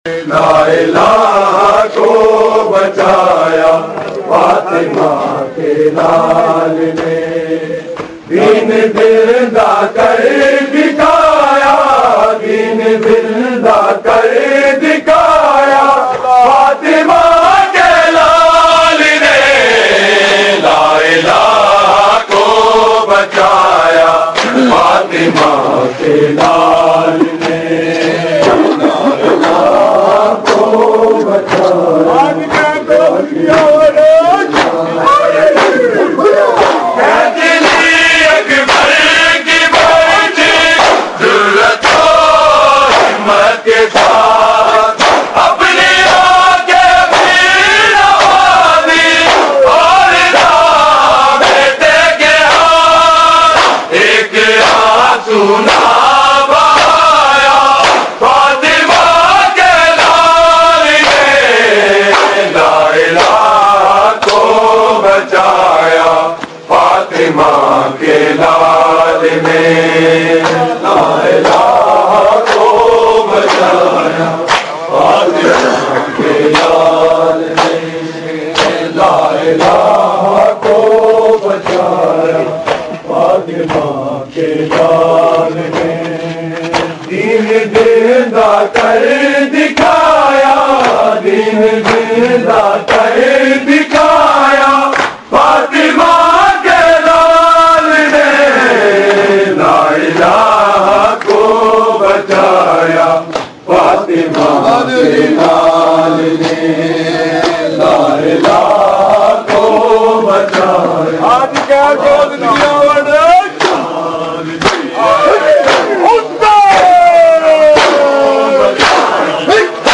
Markazi Matmi Dasta, Rawalpindi
Recording Type: Live